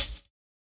Closed Hats
HI HAT CUFF.wav